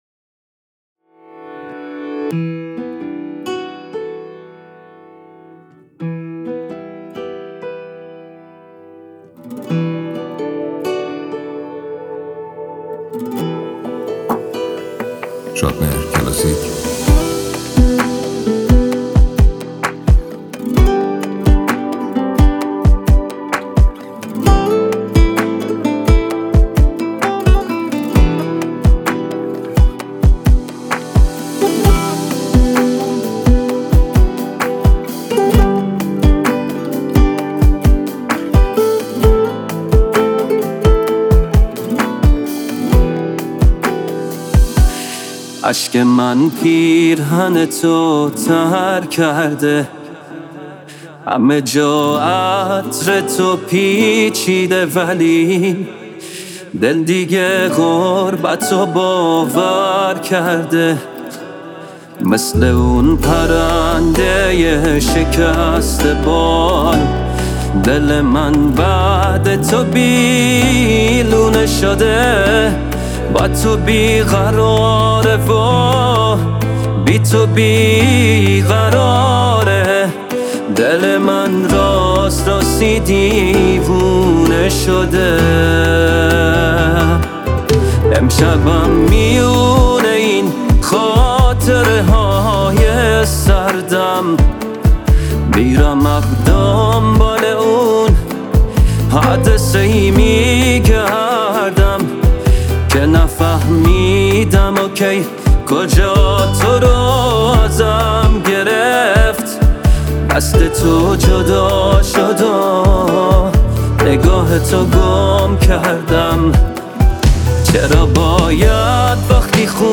ورژن آنپلاگد